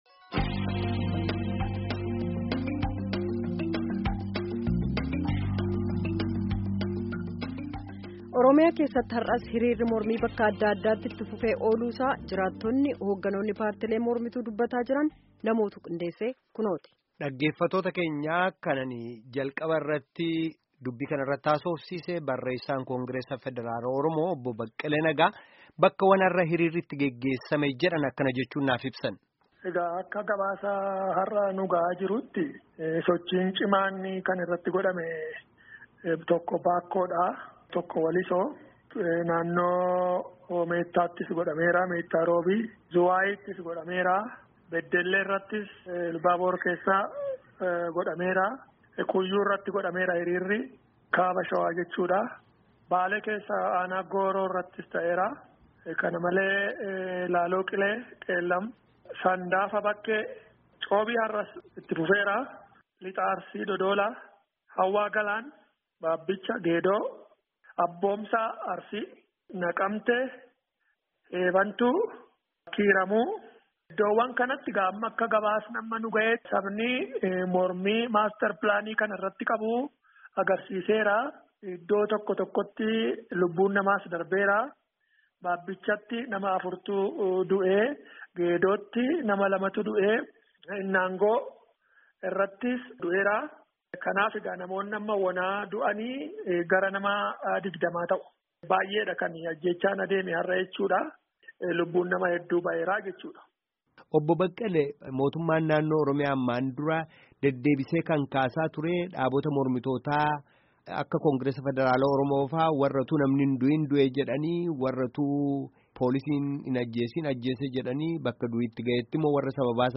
Gabaasa guutuu dhaggeffadhaa